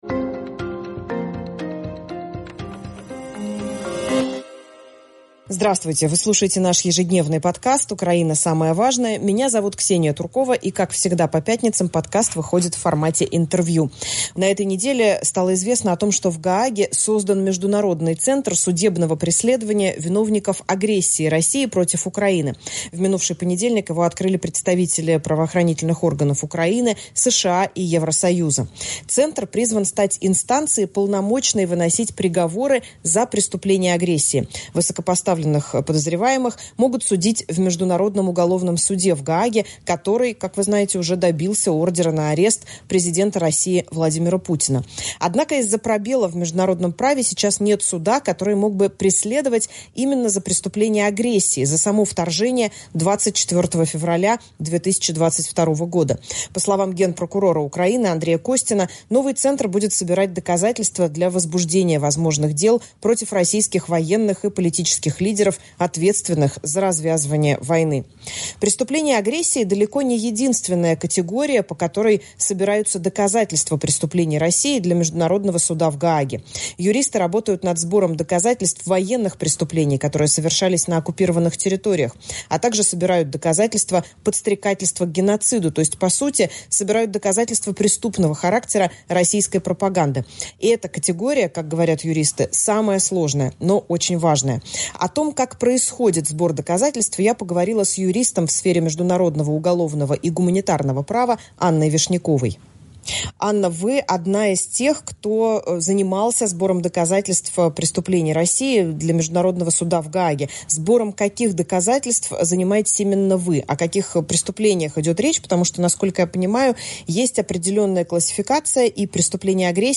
Как всегда по пятницам, подкаст выходит в формате интервью.